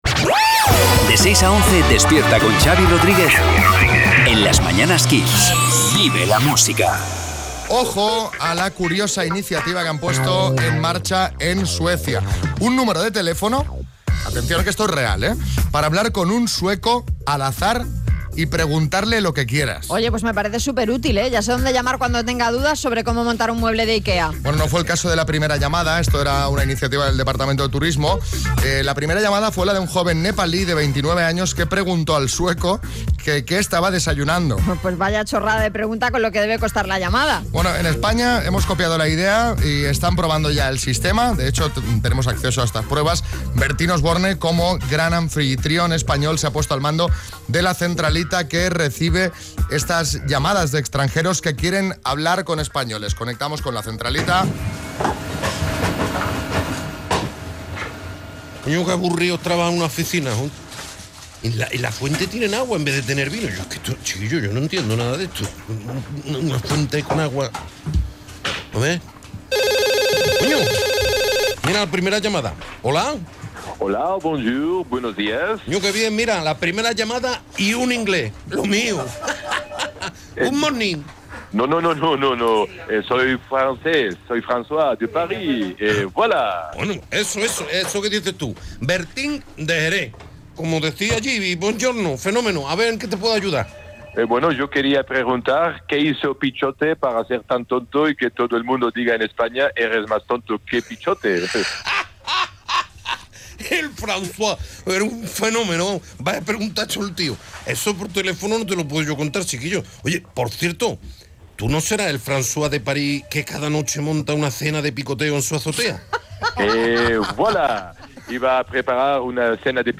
Bertín atiende llamadas de extranjeros